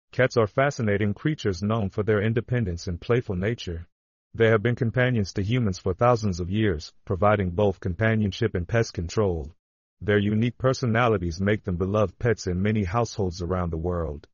Classic Text-to-Speech
You can hear classic text-to-speech in action by playing the narrations below.
Neural Voice
neural_tts_voice.mp3